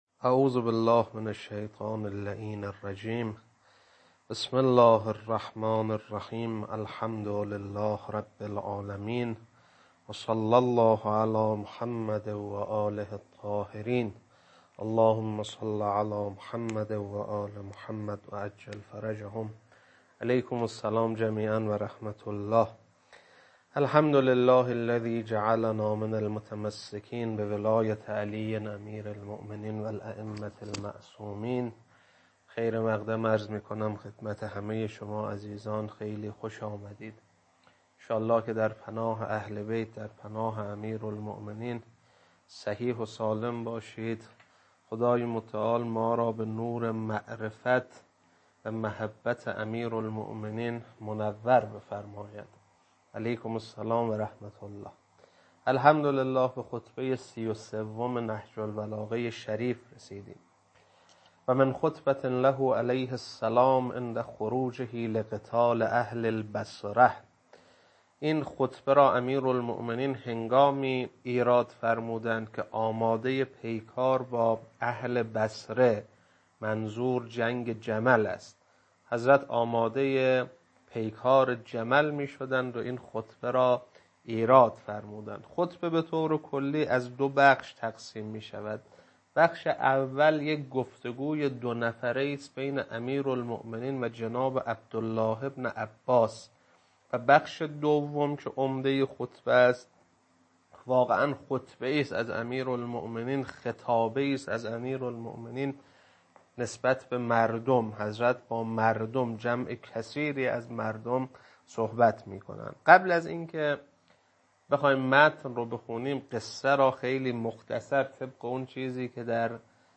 خطبه 33.mp3